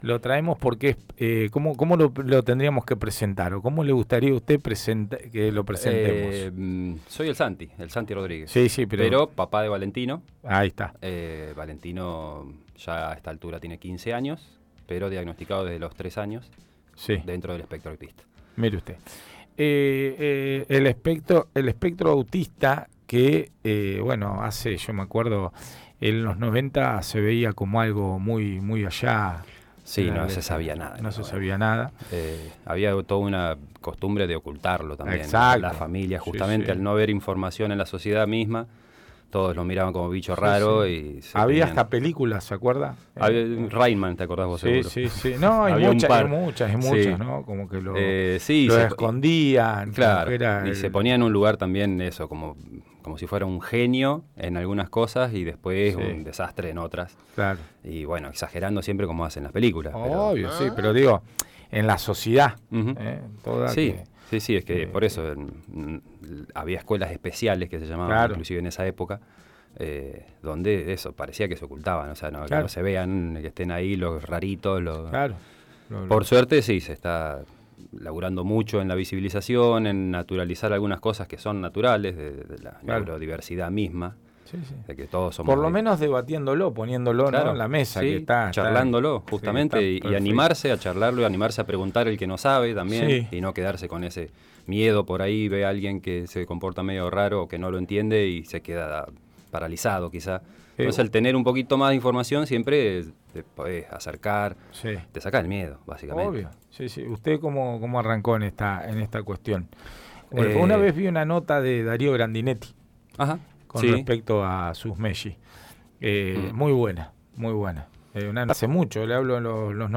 en «el mundo vive equivocado» este lunes en los estudios de Radio La Tosca